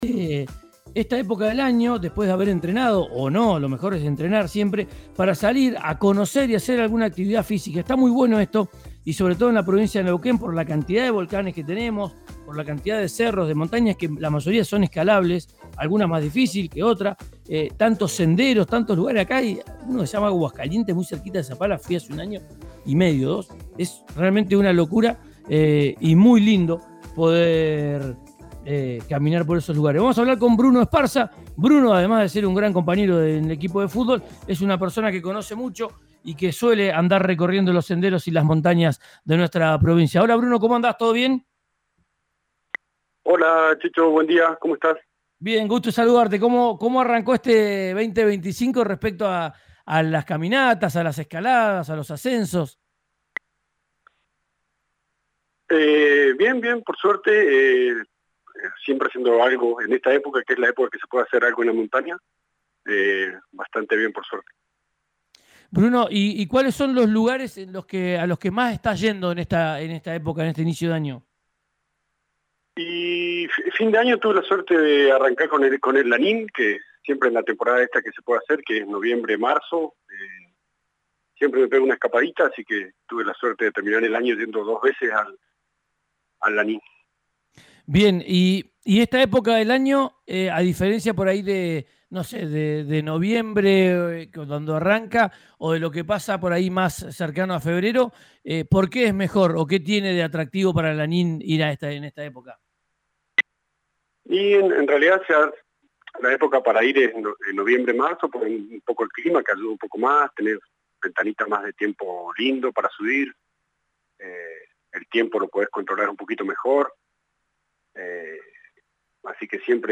RÍO NEGRO RADIO